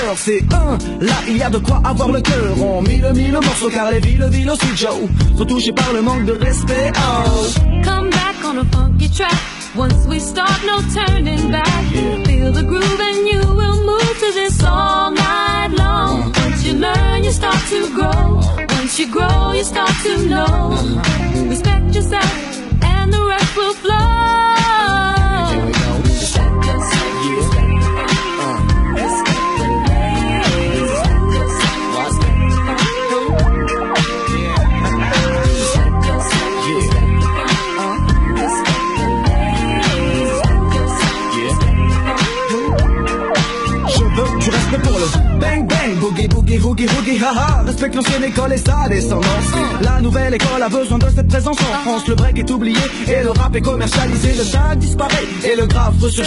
Hörproben und Mitschnitte von DRM als 56-kBit/s (oder mehr)-MP3-Dateien: Alle Beispiele sind mit dem hier beschriebenen Empfänger entstanden. Die Bandbreite des Sendesignals betrug immer 10 kHz.
Pop-Musik mit 17.46 kBit/s Klassik mit 11kBit/s
drm-popmusik.mp3